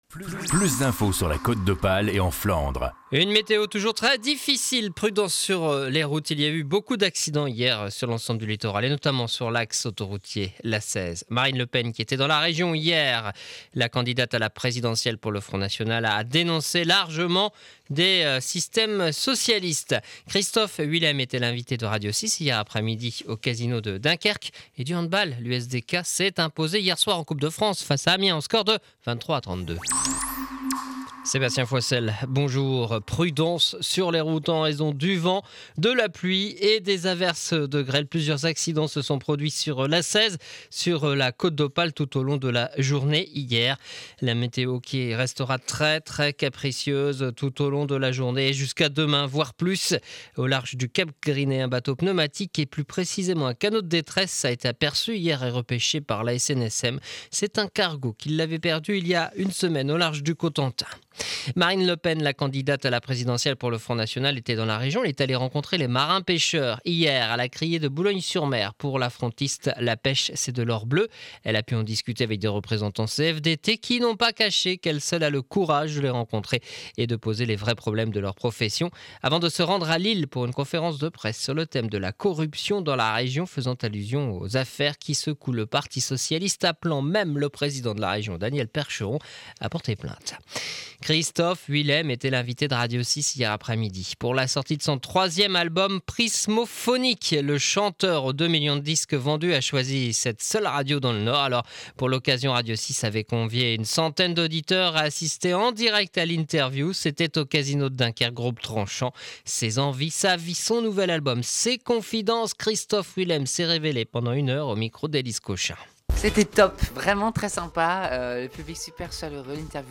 journal du jeudi 15 decembre de 7h30 à Dunkerque